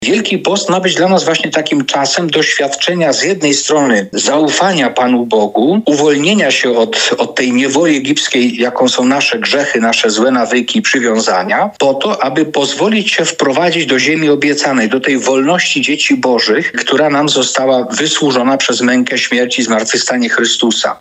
Teolog: Wielki Post to nie tylko nakazy i zakazy